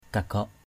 /ka-ɡ͡ɣɔʔ/